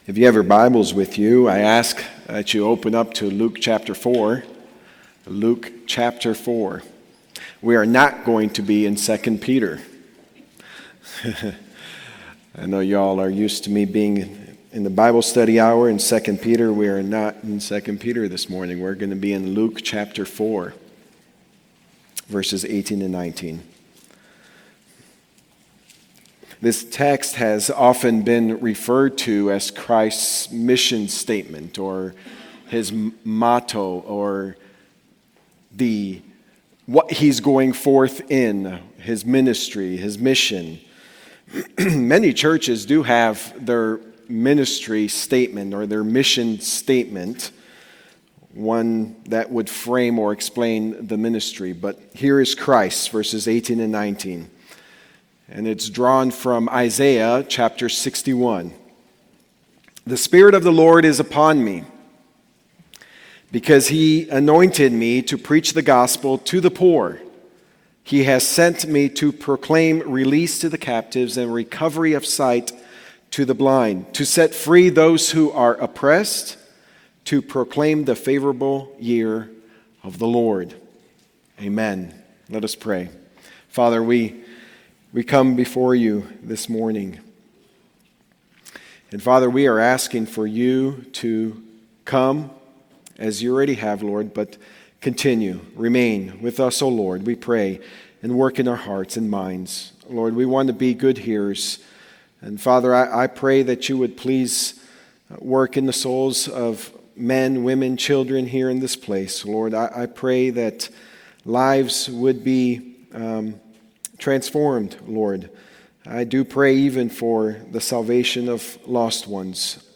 The Spirit-Filled Preacher | SermonAudio Broadcaster is Live View the Live Stream Share this sermon Disabled by adblocker Copy URL Copied!